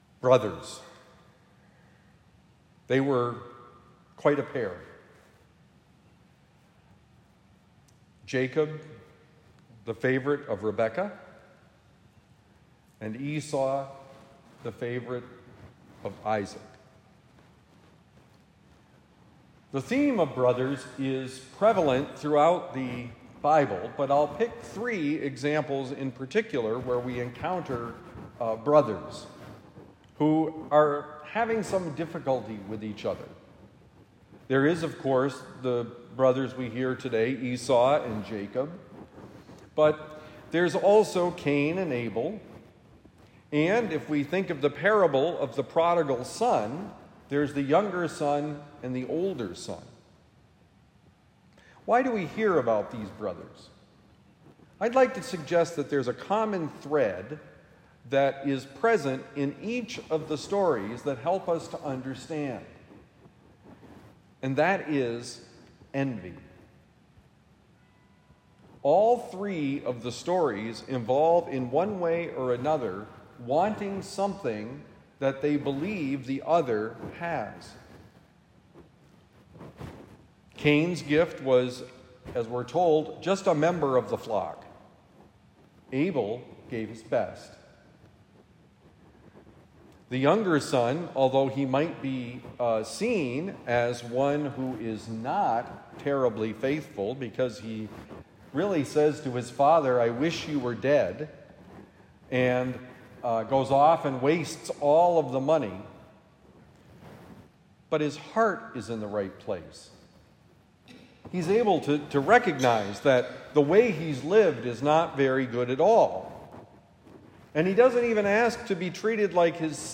They were quite the pair: Homily for Saturday, July 5, 2025